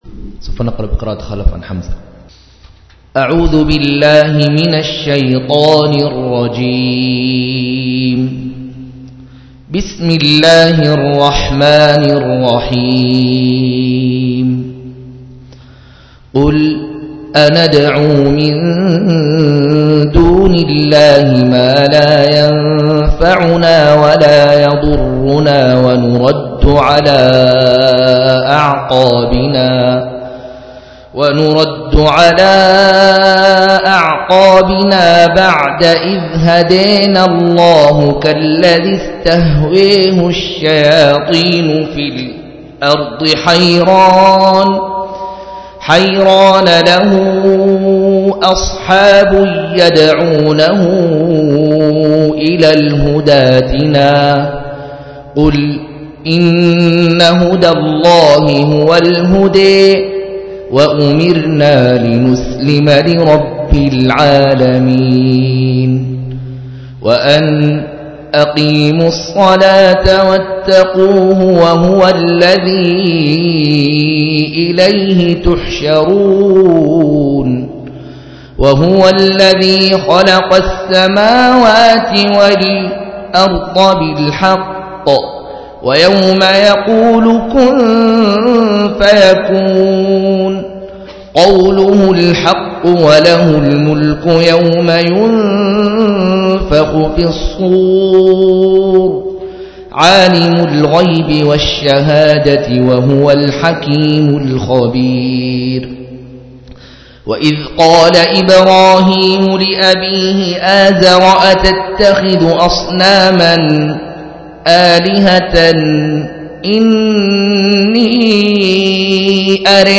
131- عمدة التفسير عن الحافظ ابن كثير رحمه الله للعلامة أحمد شاكر رحمه الله – قراءة وتعليق –